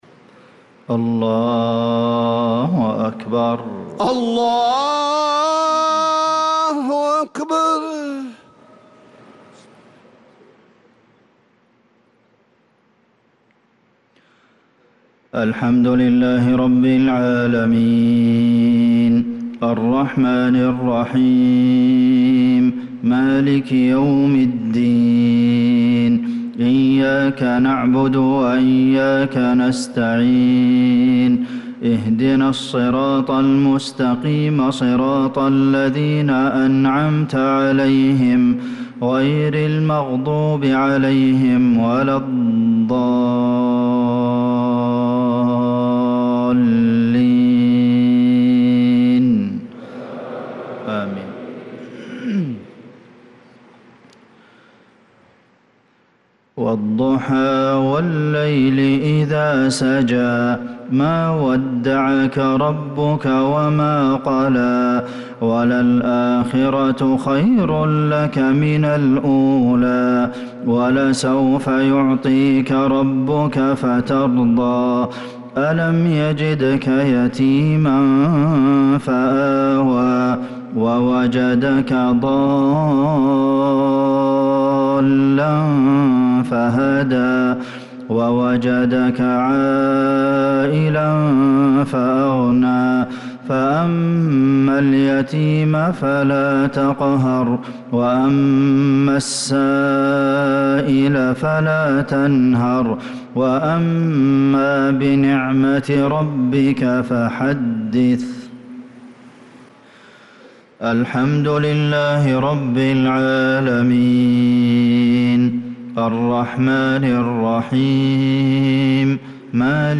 صلاة المغرب للقارئ حسين آل الشيخ 26 محرم 1446 هـ
تِلَاوَات الْحَرَمَيْن .